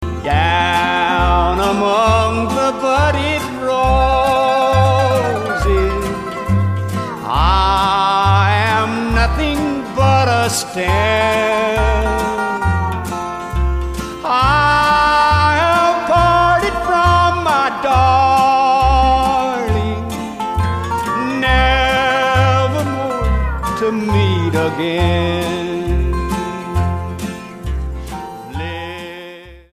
STYLE: Country
hauntingly plaintive mountain style